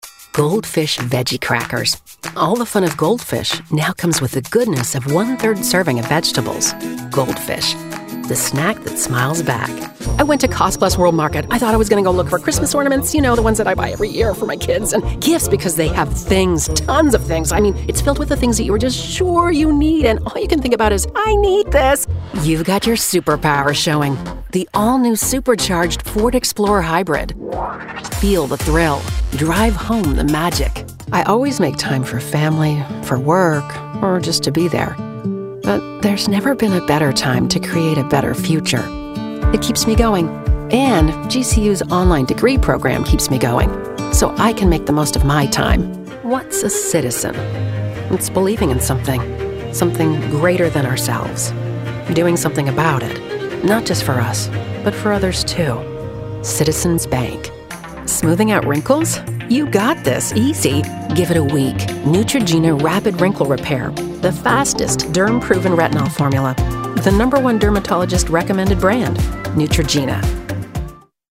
Uma voz calorosa e atenciosa para comerciais compassivos. Uma entrega confiante e relacionável para narração corporativa.
Confiável
Esquentar
Autoritário